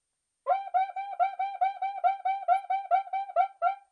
Cuica " cuica (13)
描述：桑巴batucada乐器的不同例子，发出典型的sqeaking声音。马兰士PMD671，OKM双耳或Vivanco EM35.
Tag: 巴西 图案 打击乐器 节奏 桑巴